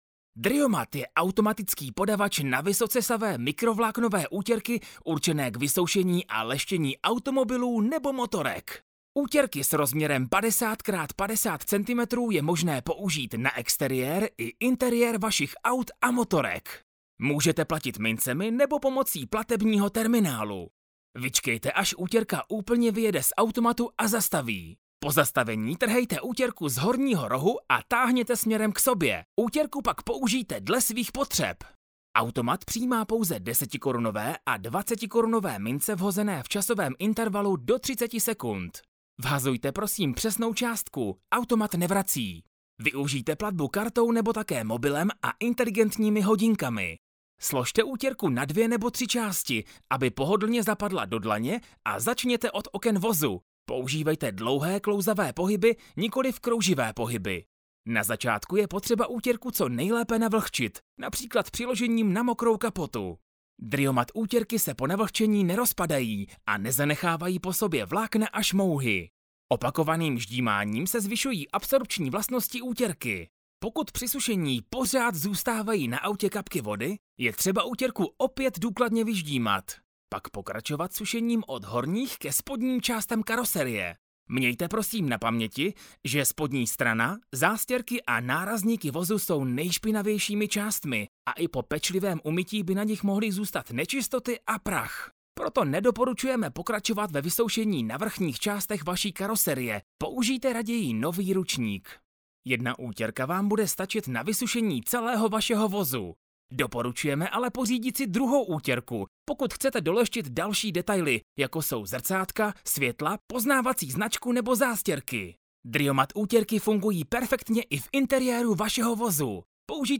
- namluvím pro Vás minutový voiceover do videa dle zadaných textových podkladů v profesionálním nahrávacím studiu
Namluvím pro Vás až minutový voiceover do vašeho videa